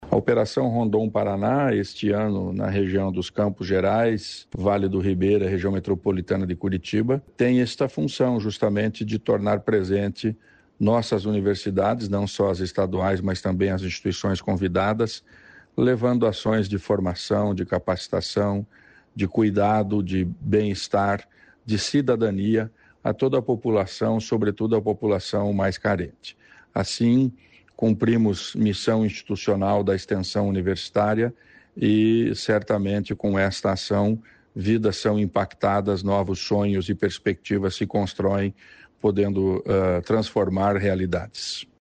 Sonora do secretário estadual da Ciência, Tecnologia e Ensino Superior, Aldo Nelson Bona, sobre o Projeto Rondon